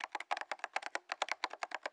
SFX_Typing_03.wav